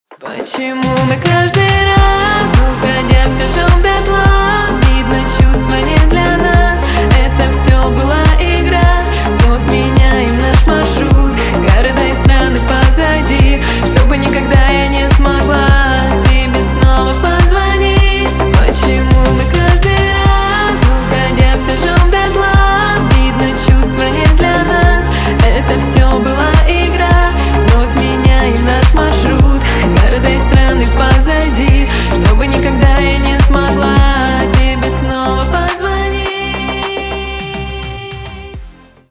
на русском грустные на парня